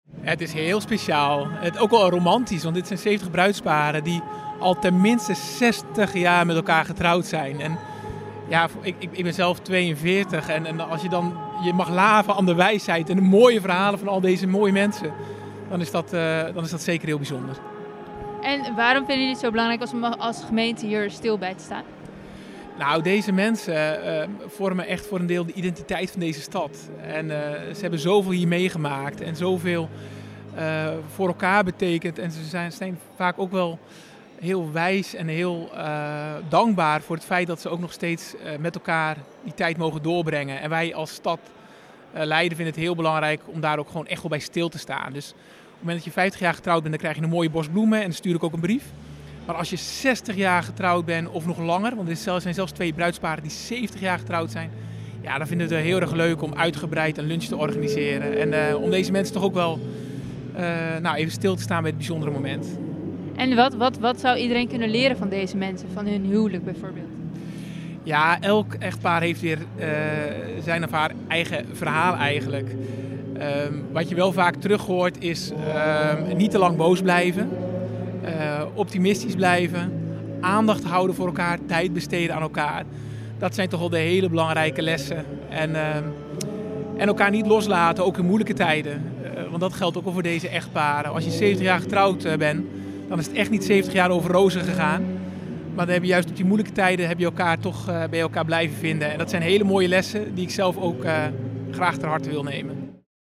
Burgemeester Peter Heijkoop over de Leidse Bruidsparenmiddag.